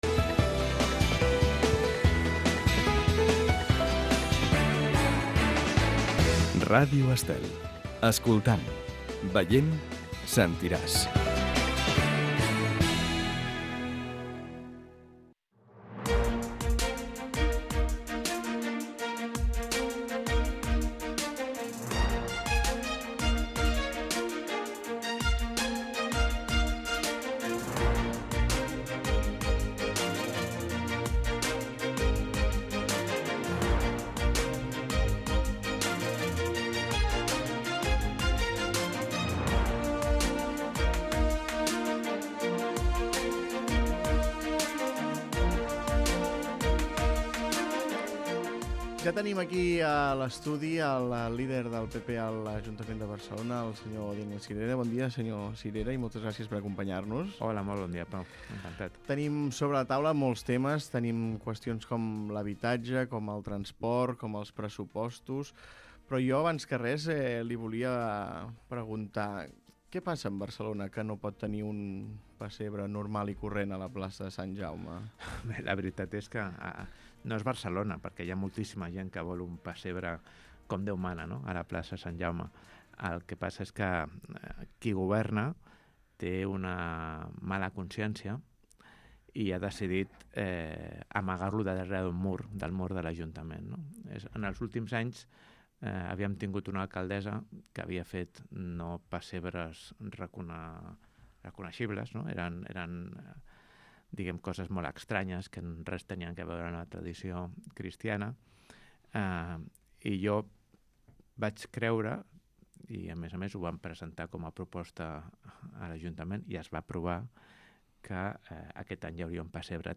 Escolta l'entrevista a Daniel Sirera, el president del Partit Popular a l'Ajuntament de Barcelona